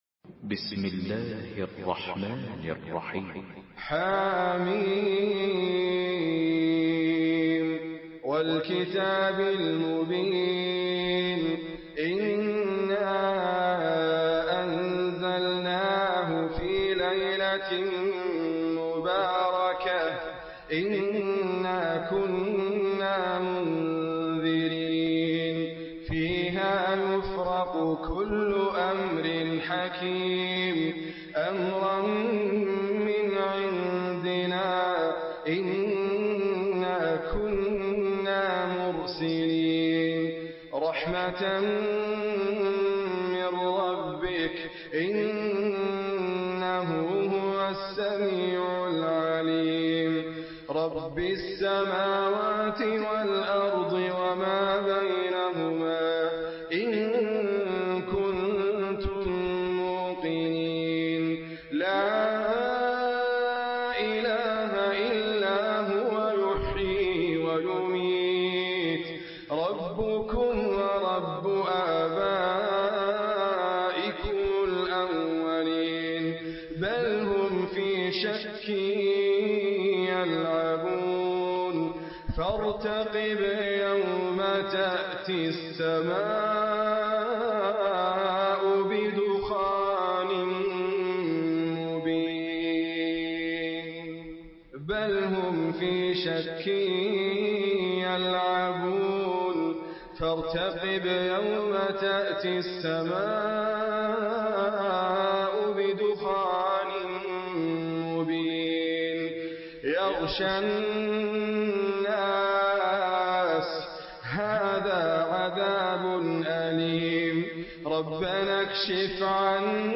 Surah Ad-Dukhan MP3 in the Voice of Idriss Abkar in Hafs Narration
Murattal